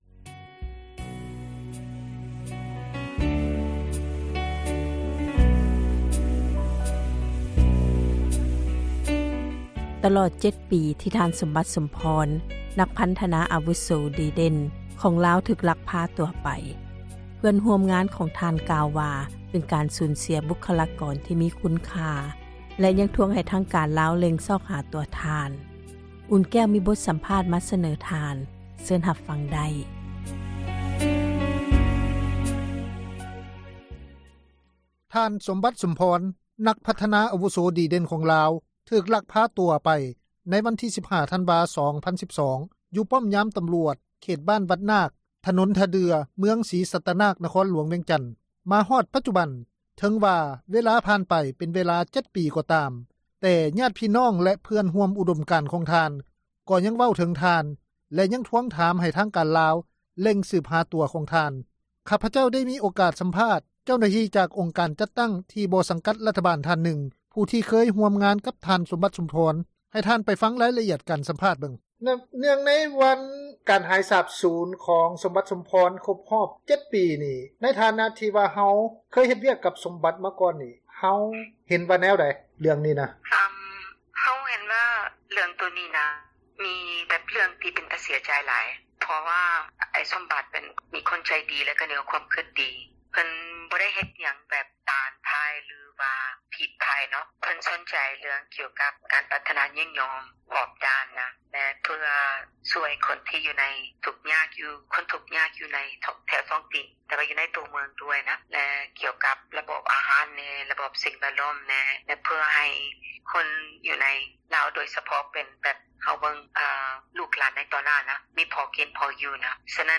ມີບົດສຳພາດ ມີສເນີ ທ່ານ.